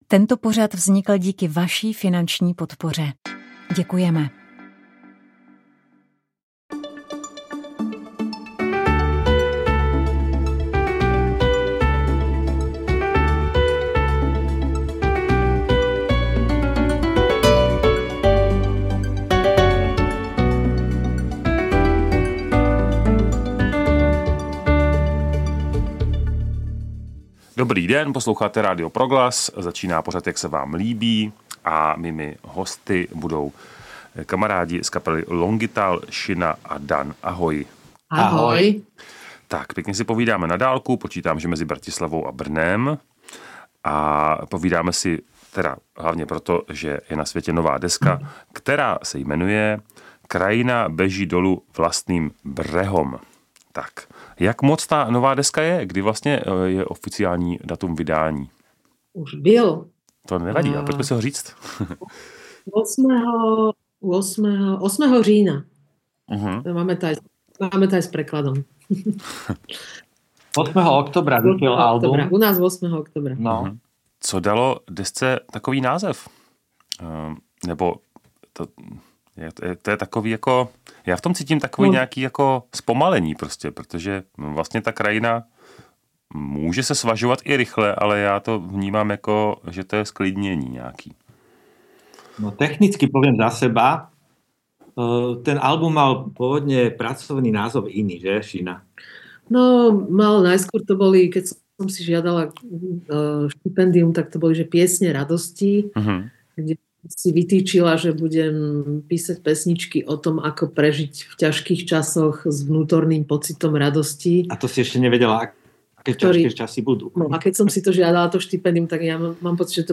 kytara, foukací harmonika, zpěv
baskytara, kontrabas
cajón
klarinet
klavír, klávesy.